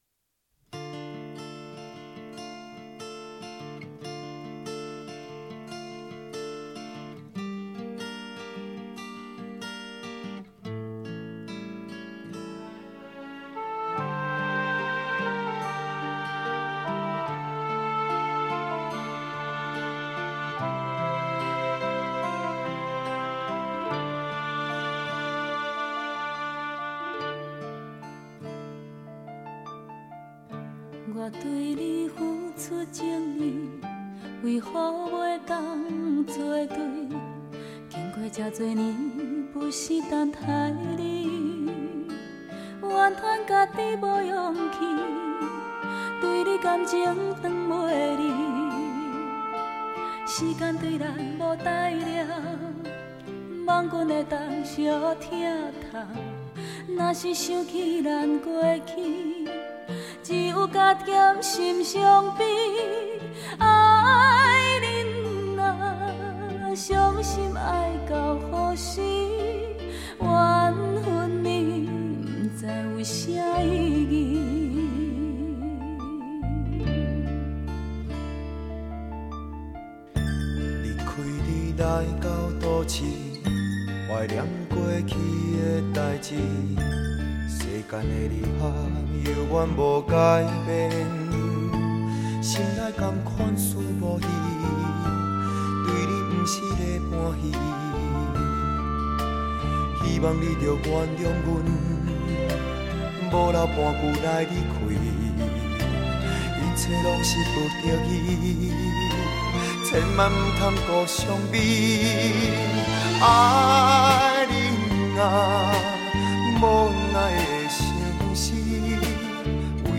柔情的噪子充满典雅怀旧的风格